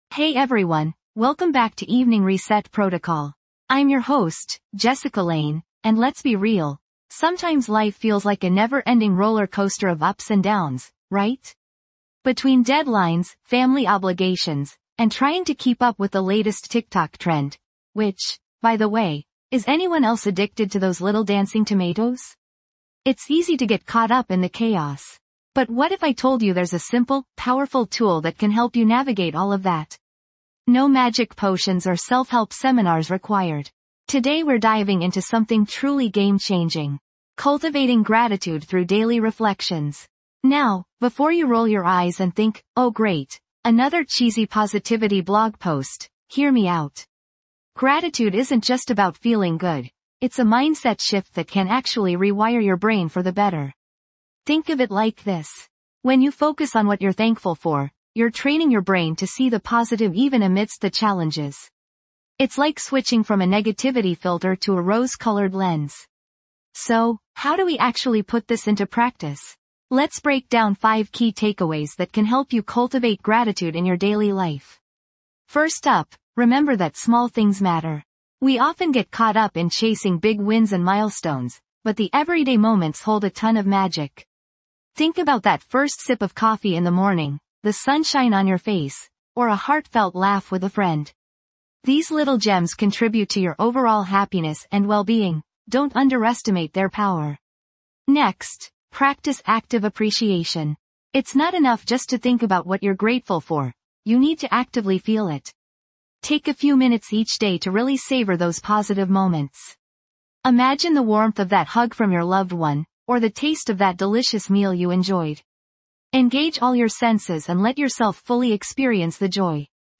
Through soothing soundscapes and carefully crafted affirmations, we'll help you release the day's burdens, rewire negative thought patterns, and cultivate a sense of inner peace.